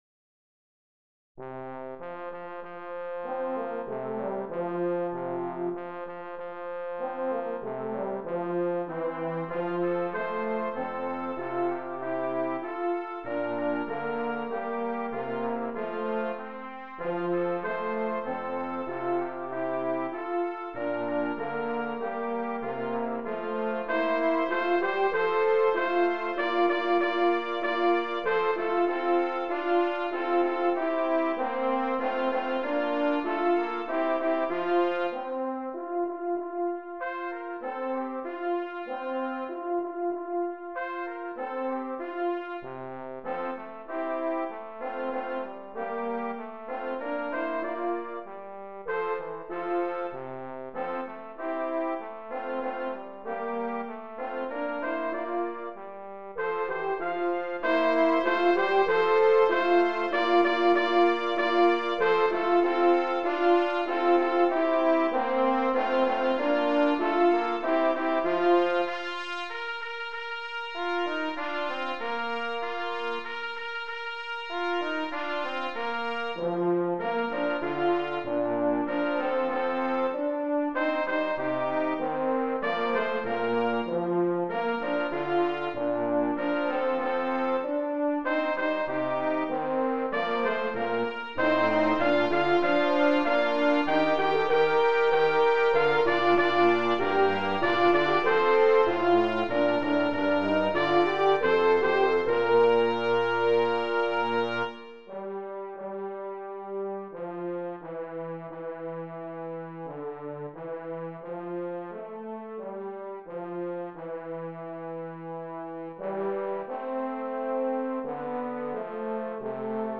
Voicing: Tpt/Gtr